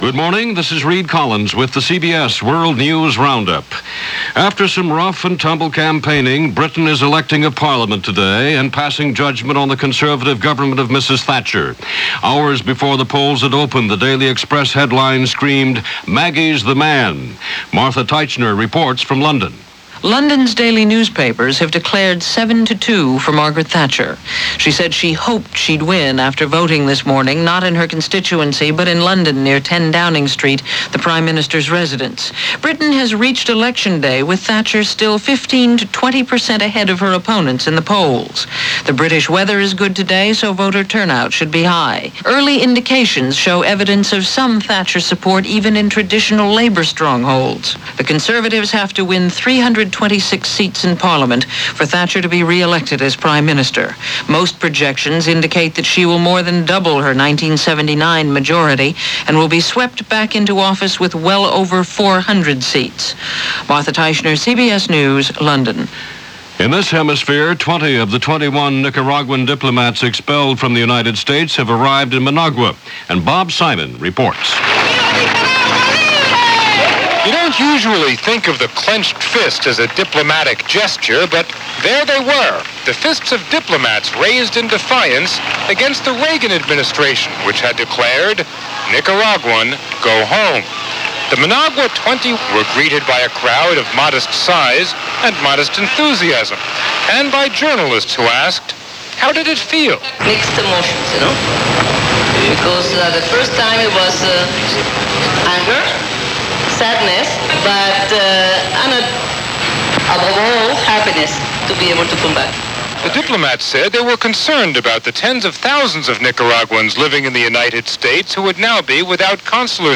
CBS World News Roundup – Hourly News